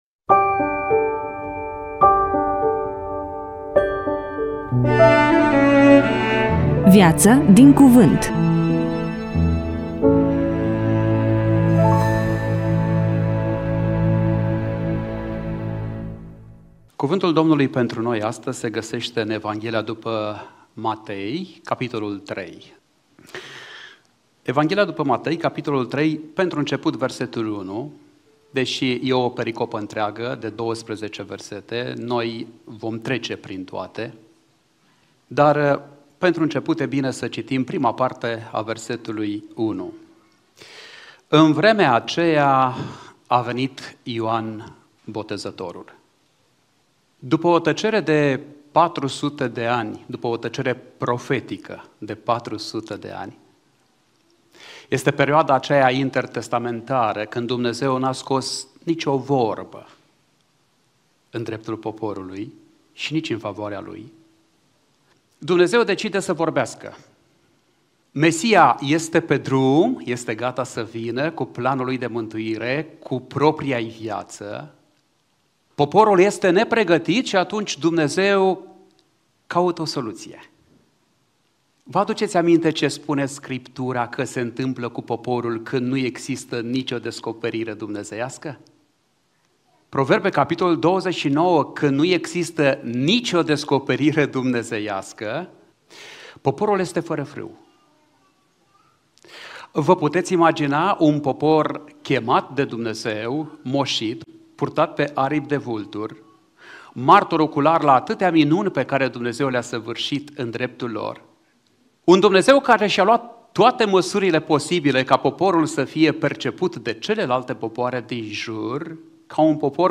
EMISIUNEA: Predică DATA INREGISTRARII: 24.01.2026 VIZUALIZARI: 5